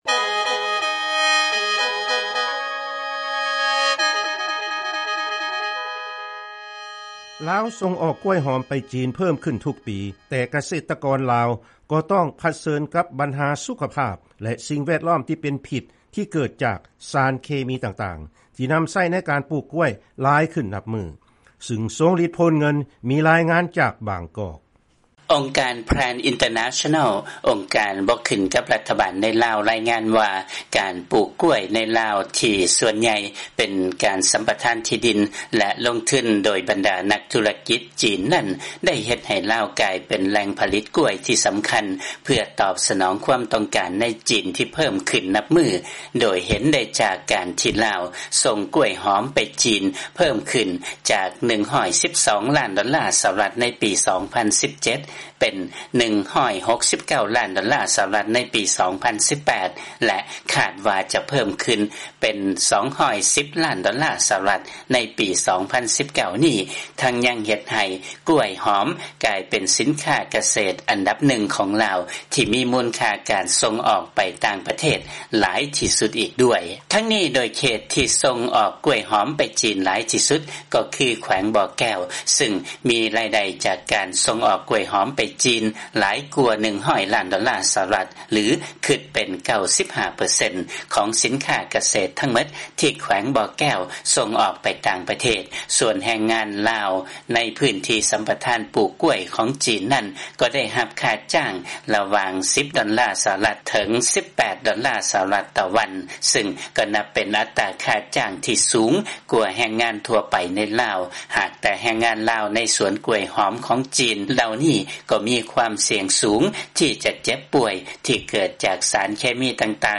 ຟັງລາຍງານ ລາວ ສົ່ງອອກກ້ວມຫອມໄປ ຈີນ ເພີ່ມຂຶ້ນທຸກປີ ແຕ່ກະສິກອນ ລາວ ກໍຕ້ອງປະເຊີນກັບ ບັນຫາສຸຂະພາບ